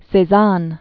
(sā-zăn, -zän), Paul 1839-1906.